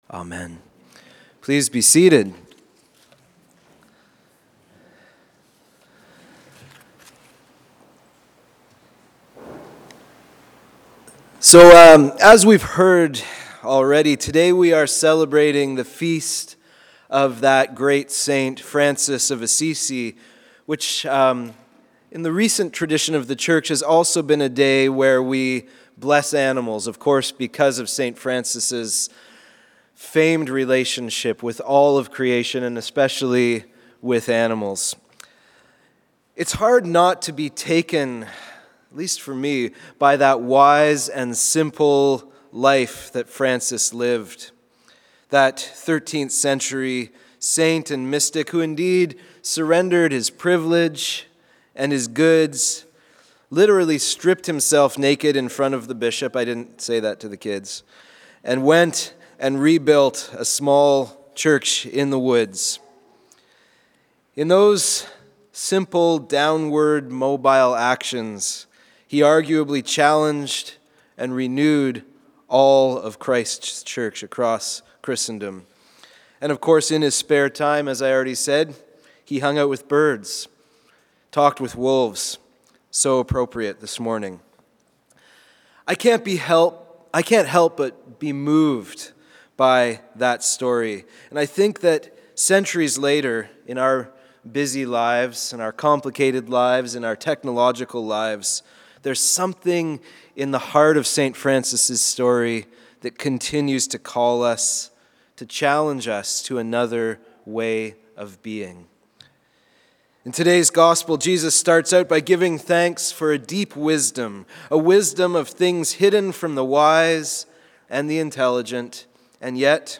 Sermon for Oct 27th, 2019